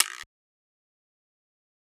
Dro Grind Perc.wav